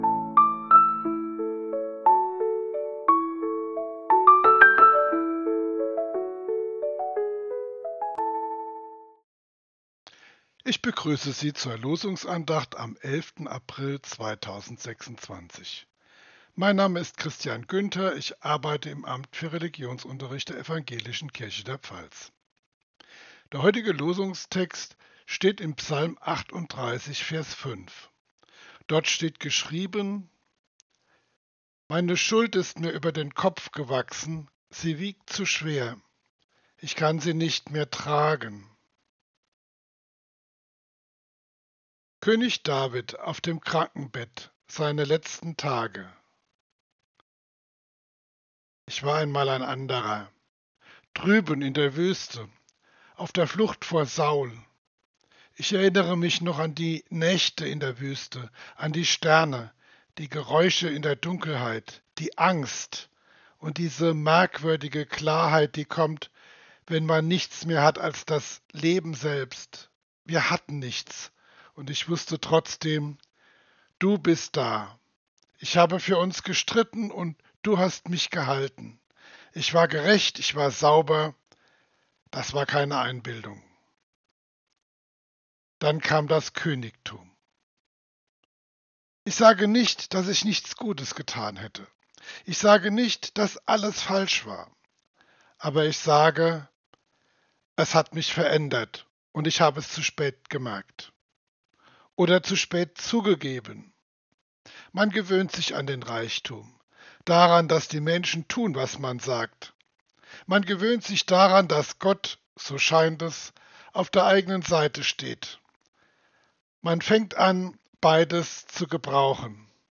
Losungsandacht für Samstag, 11.04.2026